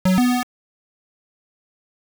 catch.wav